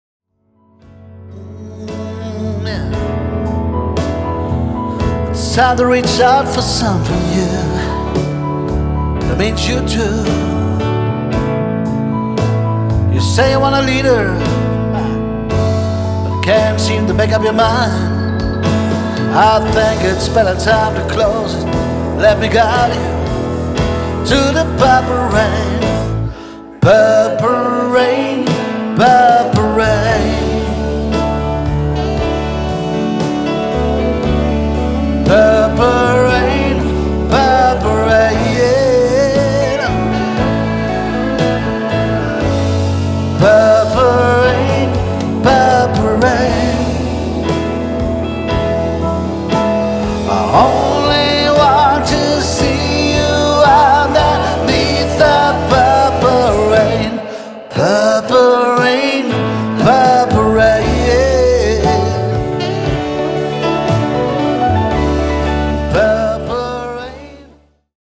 Demoaufnahmen